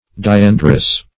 Search Result for " diandrous" : The Collaborative International Dictionary of English v.0.48: Diandrous \Di*an"drous\, n. [Cf. F. diandre.]
diandrous.mp3